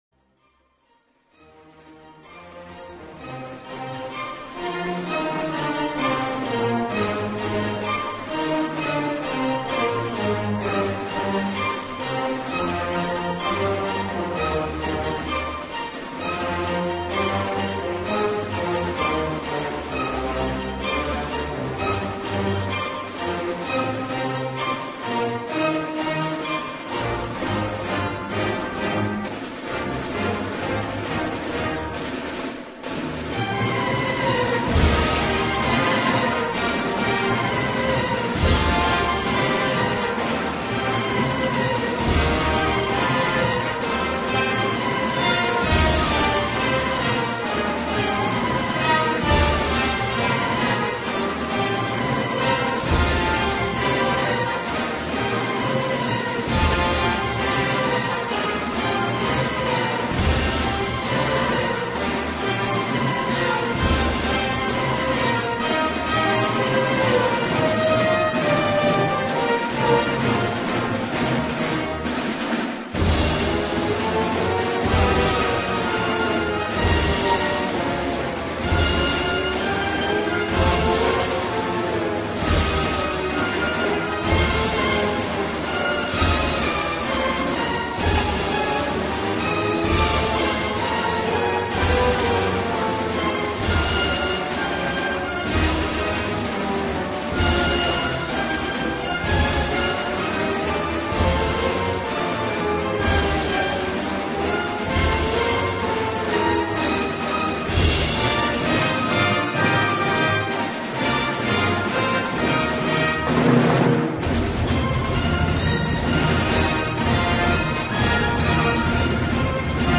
Фрагмент Седьмой («Ленинградской») симфонии Дмитрия Шостаковича